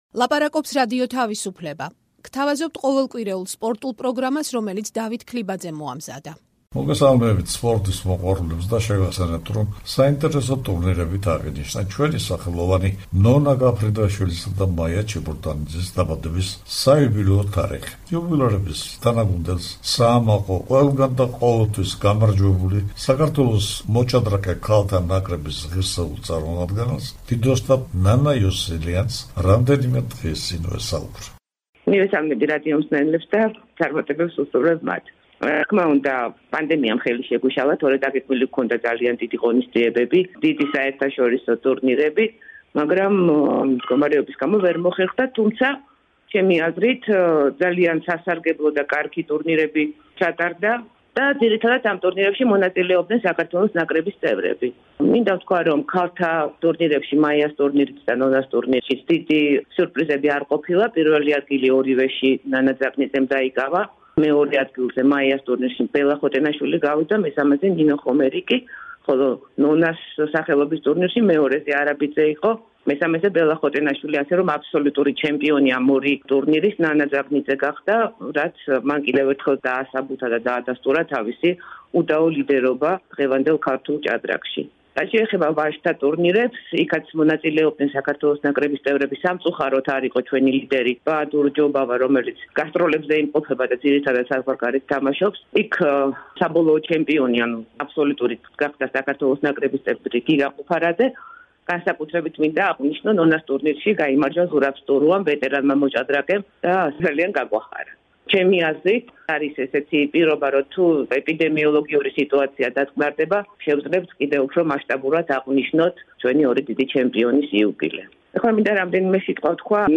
ნონა გაფრინდაშვილის და მაია ჩიბურდანიძის დაბადების საიუბილეო თარიღების აღსანიშნავ ტურნირზე საუბრობს დიდოსტატი ნანა იოსელიანი; ამ ტურნირში გამარჯვებული დიდოსტატი ნანა ძაგნიძე ხორვატიიდან შეაფასებს იქ მიმდინარე ევროპის გუნდების ჩემპიონატის მიმდინარეობას, სადაც „მონაკოს“ შემადგენლობაში უკვე...